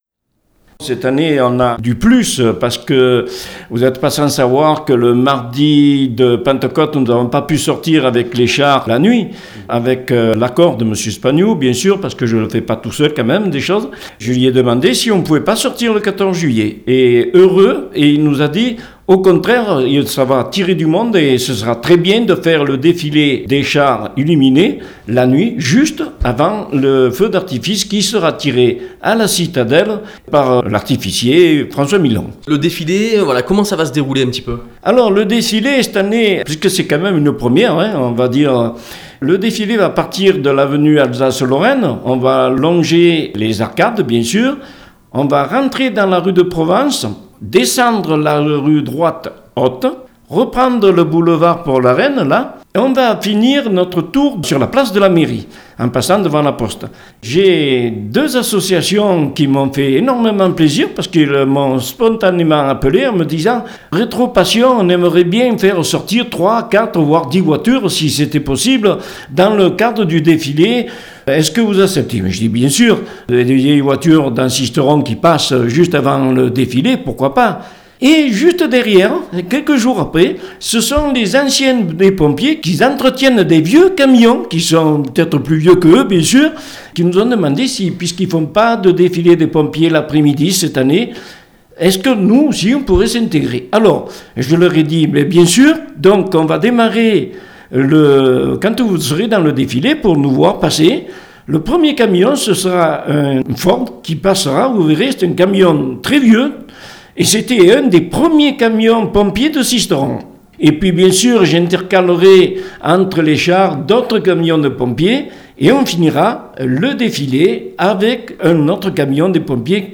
en dit plus au micro